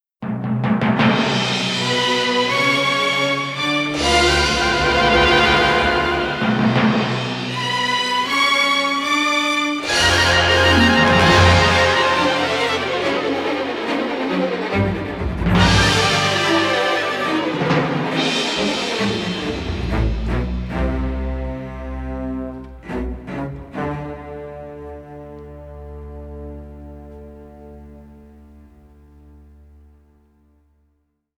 tense suspense music